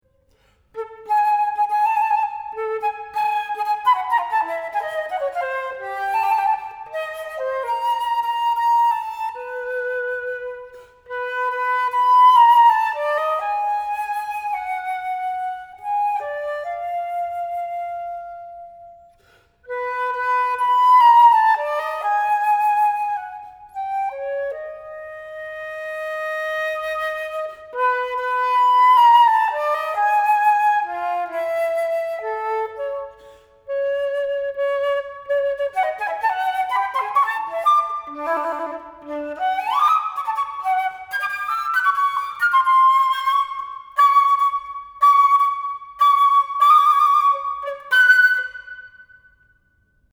Four Pieces for Solo Flute